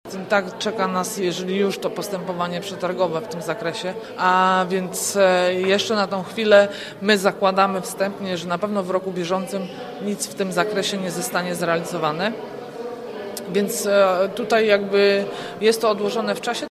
Mówi wiceprezydent Agnieszka Surmacz: